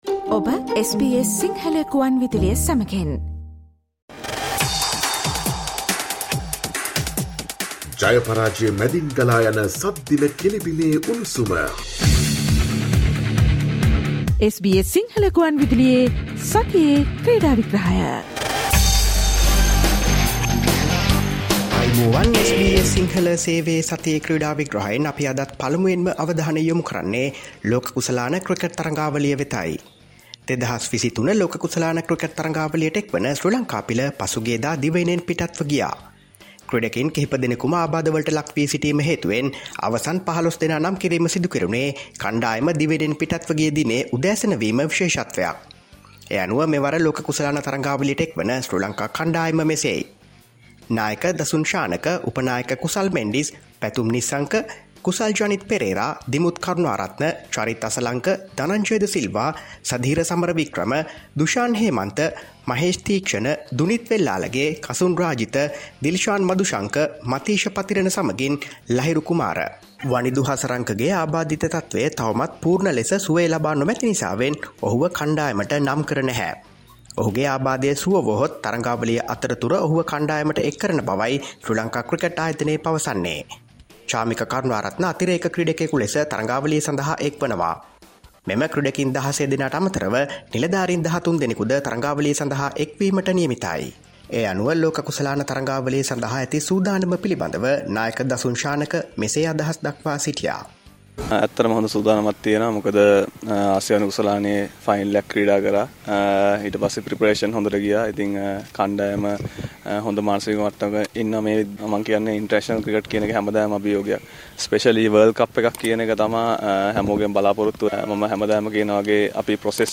Listen to the SBS Sinhala Radio weekly sports highlights every Friday from 11 am onwards.